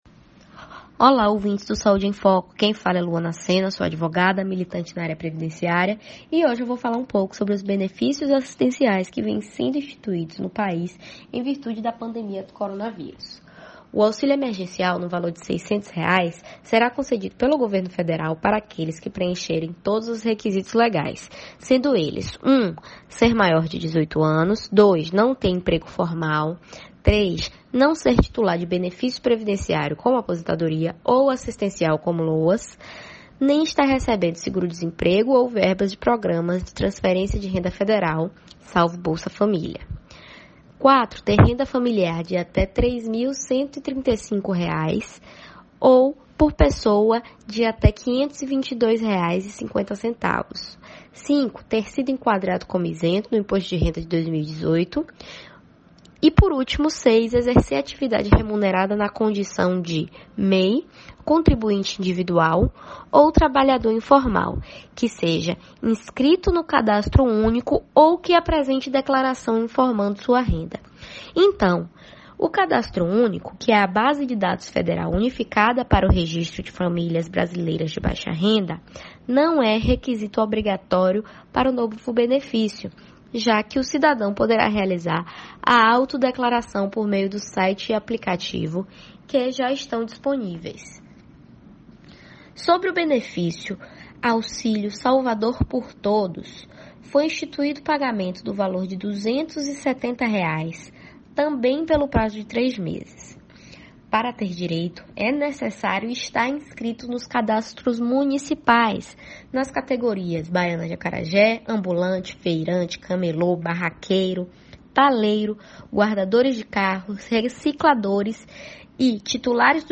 Ouça o áudio do programa: